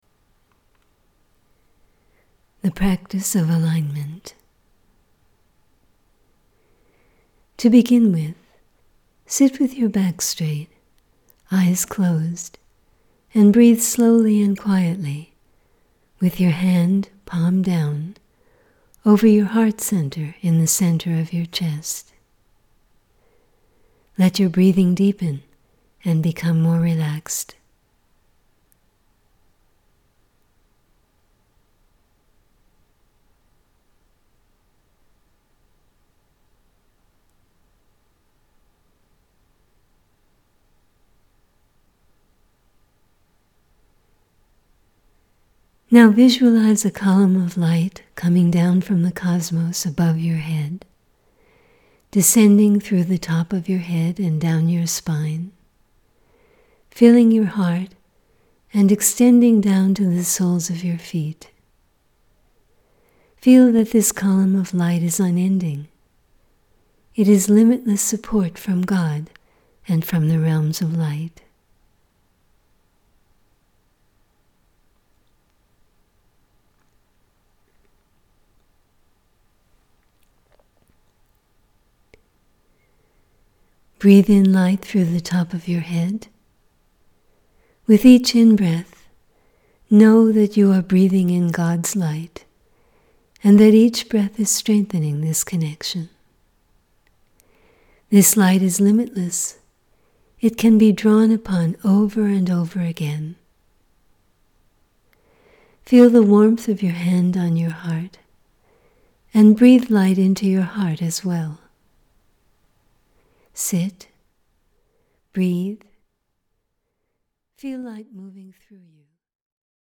For this reason, a group of meditative practices taken from the Calendar of Light are being offered for daily listening and attunement, accompanied by a booklet which introduces each practice and its purpose.